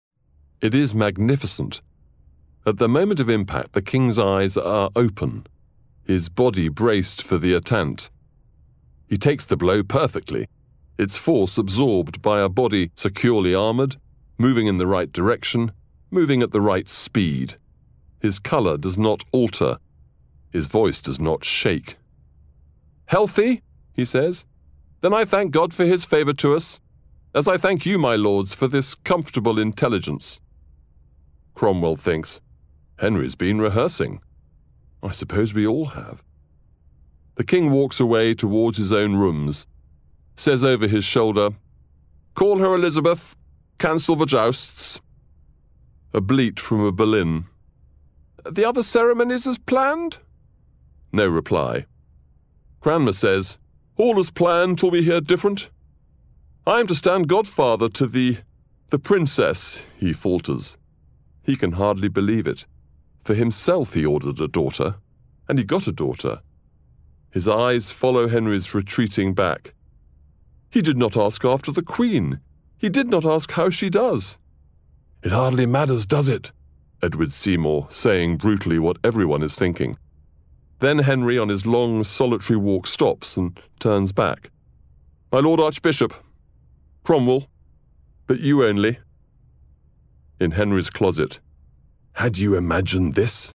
NOVEL: READING
NOVELS-WOLF-HALL.mp3